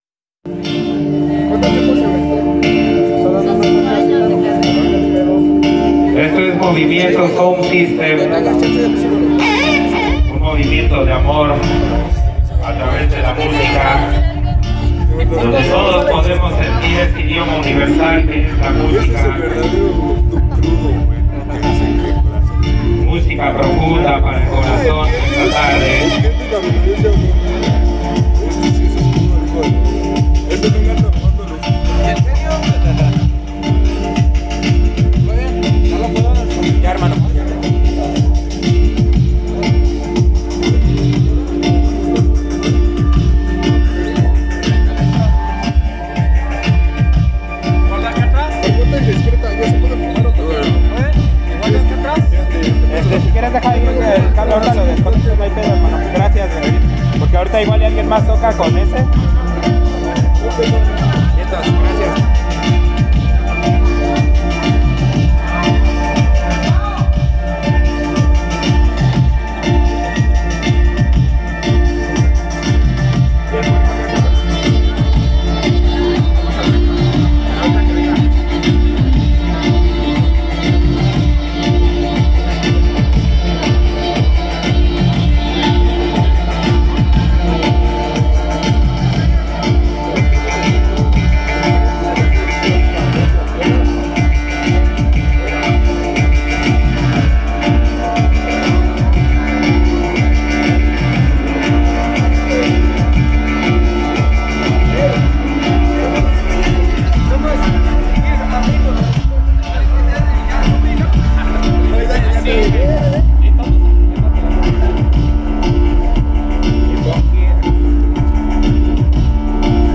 Sesión grabada en vivo